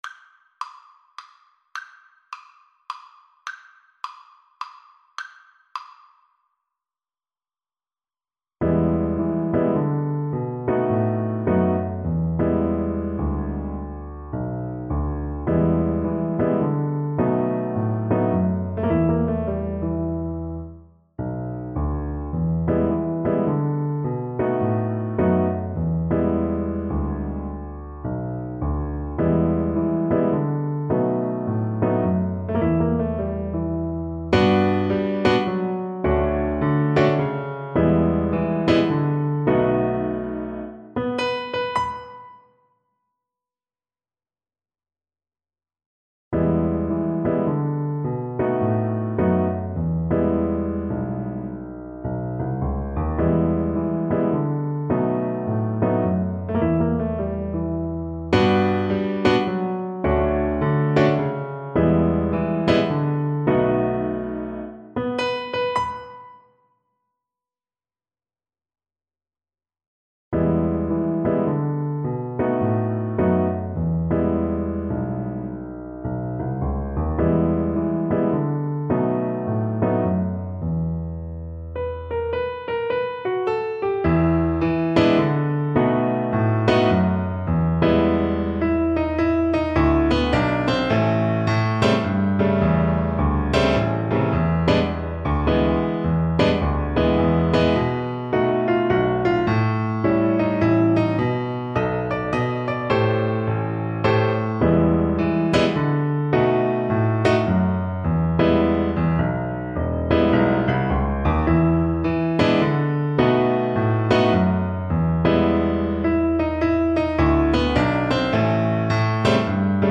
3/4 (View more 3/4 Music)
=140 Fast swing